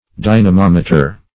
Dynamometer \Dy`na*mom"e*ter\, n. [Cf. F. dynamom[`e]tre. See